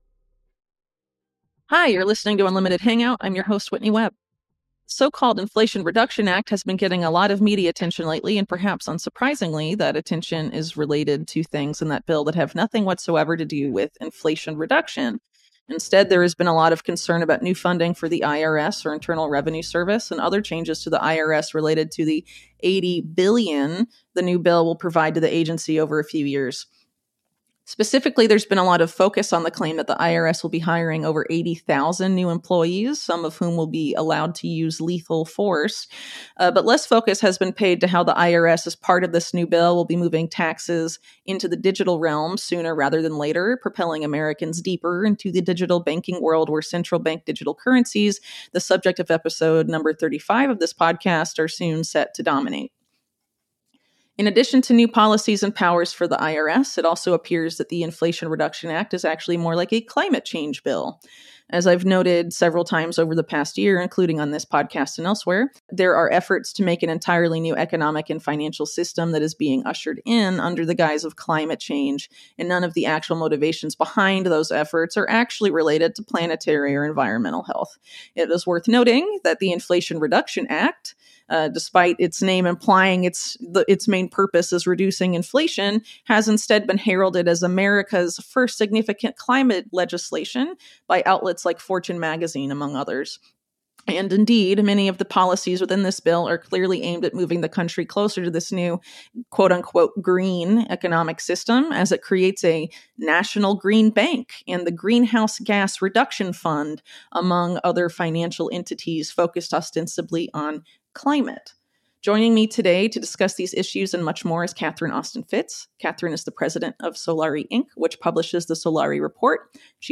Inflation Reduction & “Green” Banking with Catherine Austin Fitts (Whitney Webb interviews Catherine Austin Fitts; 30 Aug 2022) | Padverb